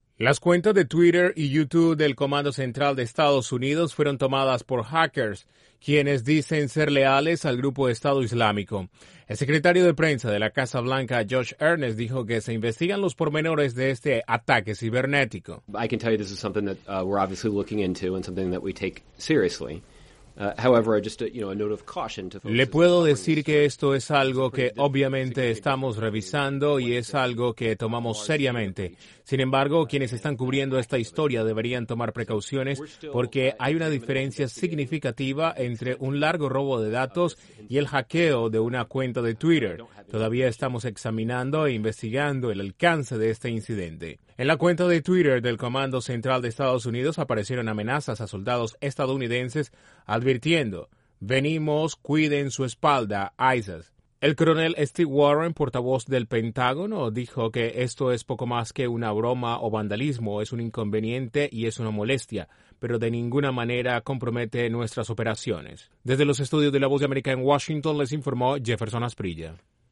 La cuenta de Twitter del Comando Central de EEUU fue tomada por hackers por lo que la Casa Blanca informó que se investiga el incidente. Desde la Voz de América en Washington informa